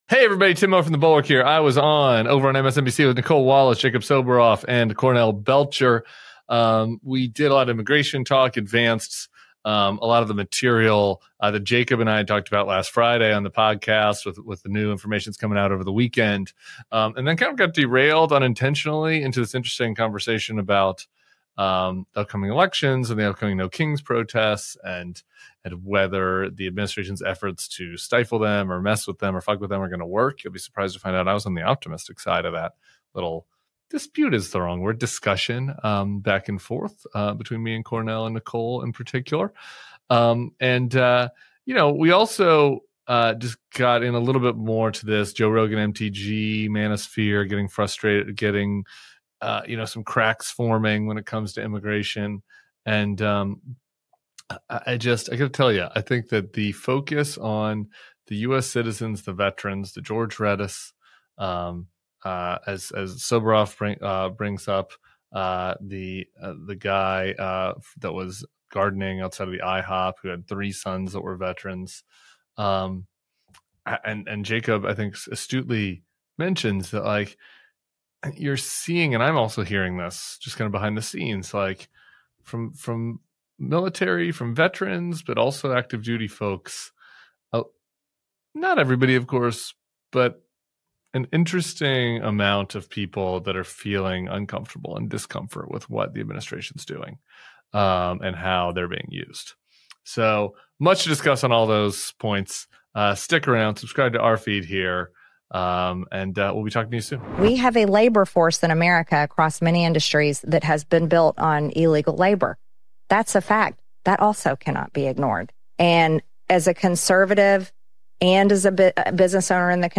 Tim Miller joins MSNBC to talk about the immigration fallout, the absurd smear of the “No Kings” protests, and cracks forming in the MAGA world as Trump’s tactics backfire.